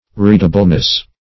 readableness - definition of readableness - synonyms, pronunciation, spelling from Free Dictionary